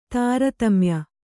♪ tāratamya